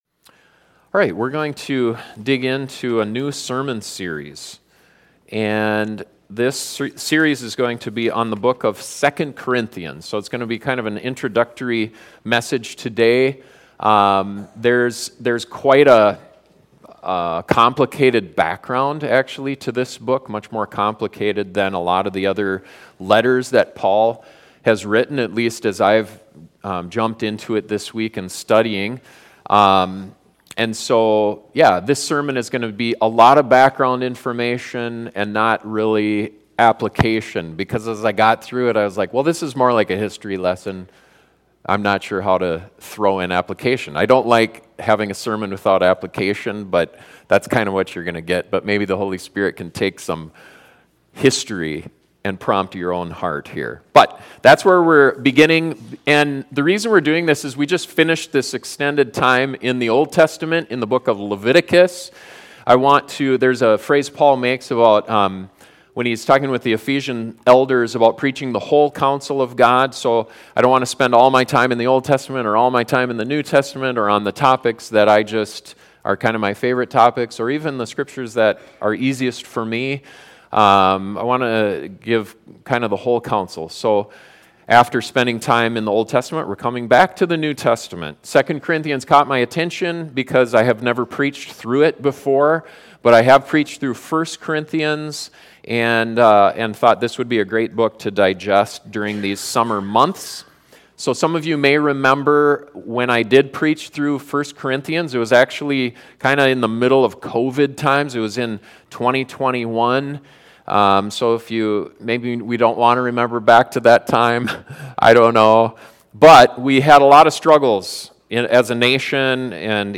The book of 2 Corinthians has a short, but complex history leading up to it. This introductory sermon gives a good foundation for understanding the content of the book and applying its principles to your life.